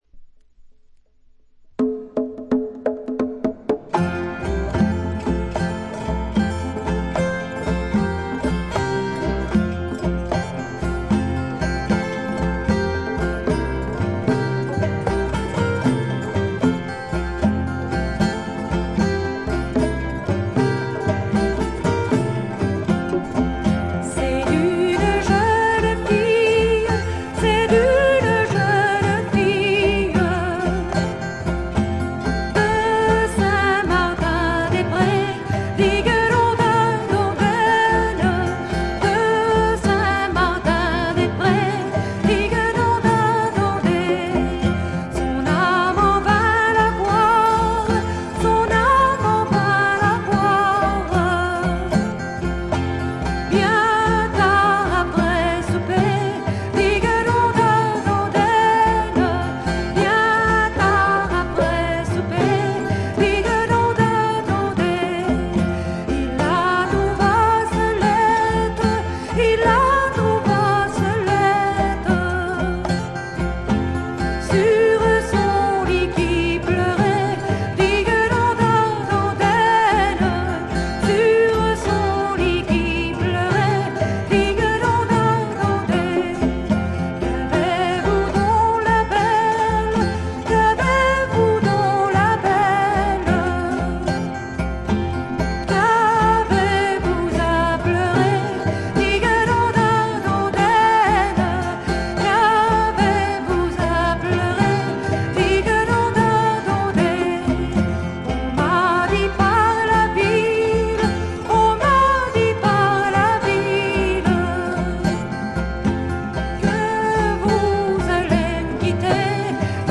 静音部の微細なチリプチのみでほとんどノイズ感無し。
フランスの女性フォーク・シンガー。
試聴曲は現品からの取り込み音源です。
Vocals
Guitar, Autoharp, Harmonica, Flageolet
Double Bass
Percussion, Violin
Recorded At - Studio D'Auteuil